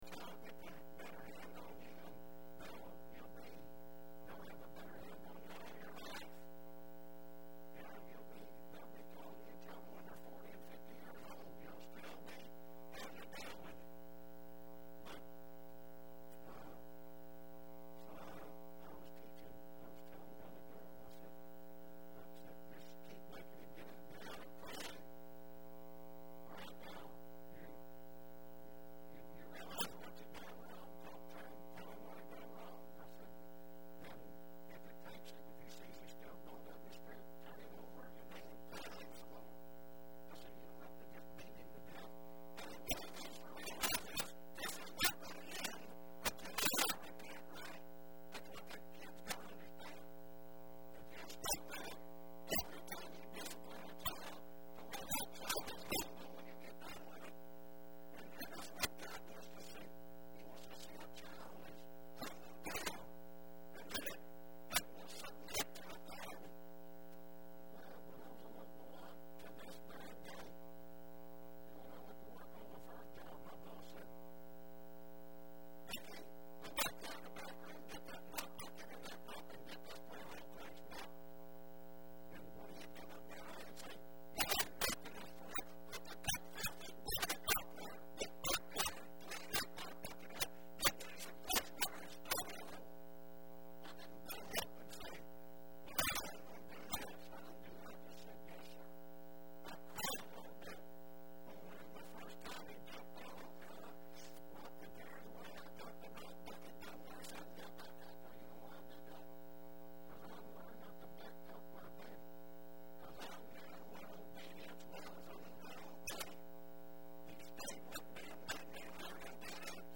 4/21/10 Wednesday Service
Wednesday Evening: 100421_1A.mp3 , 100421_1B.mp3